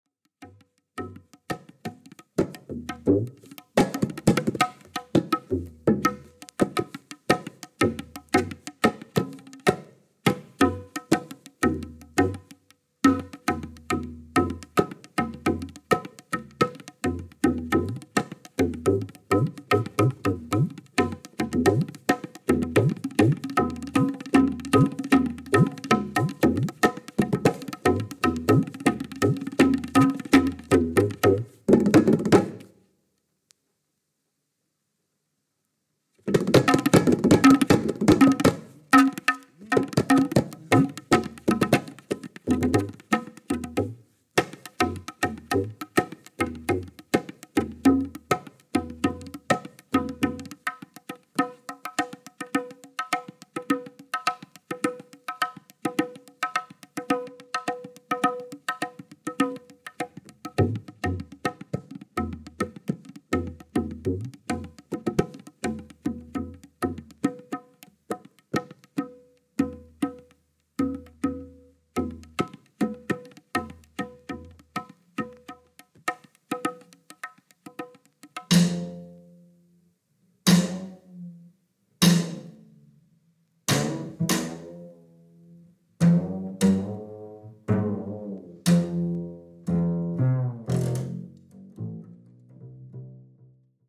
Alto Saxophone
Bass
Drums